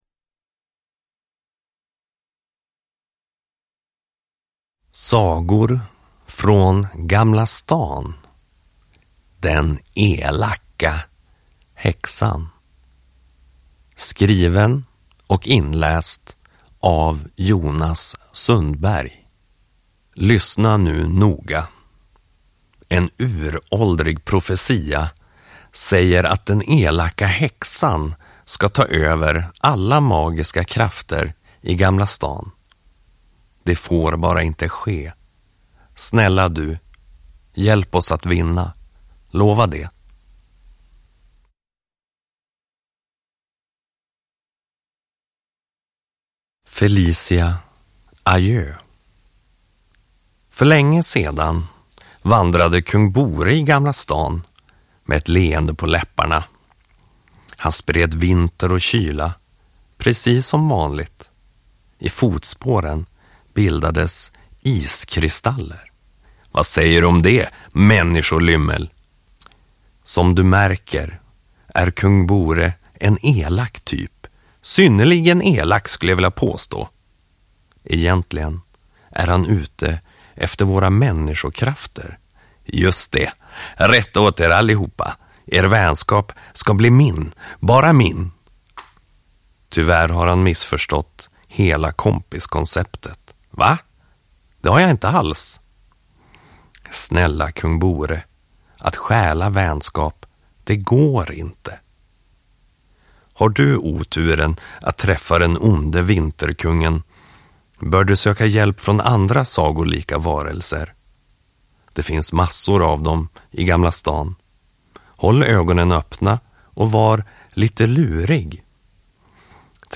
Den elaka häxan – Ljudbok